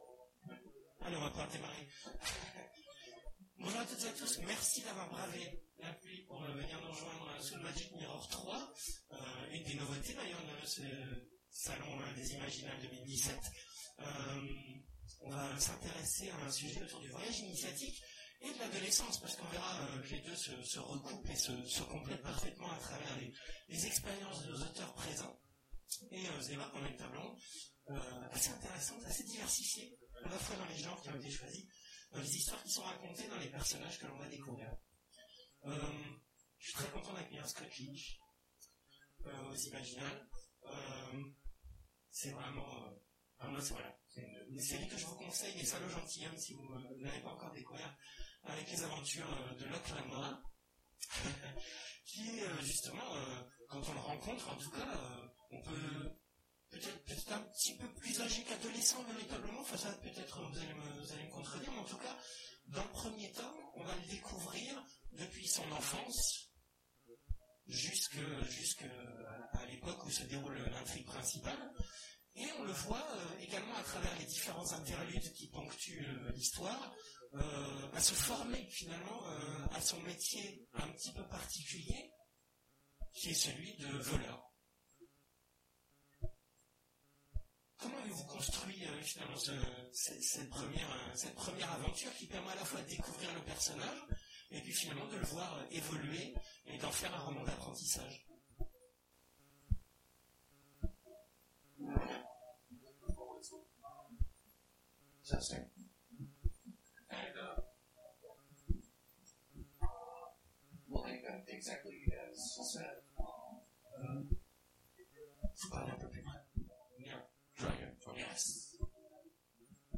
Mots-clés Voyage initiatique Conférence Partager cet article